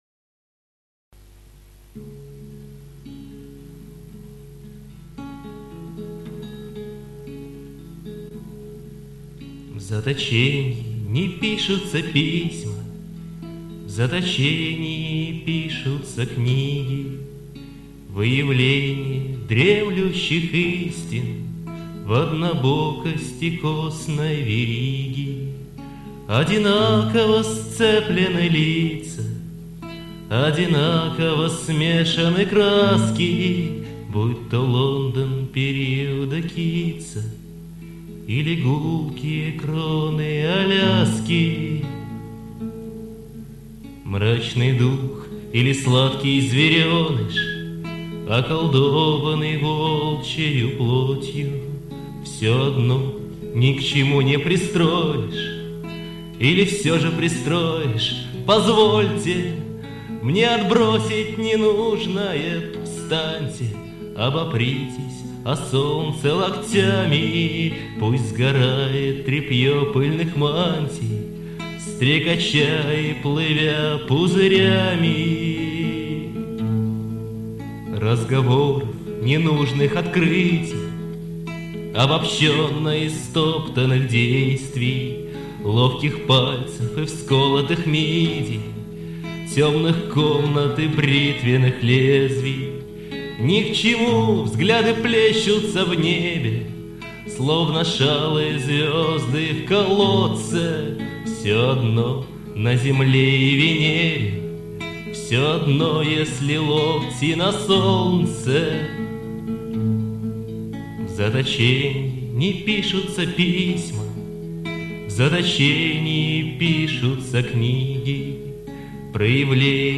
вокал, гитара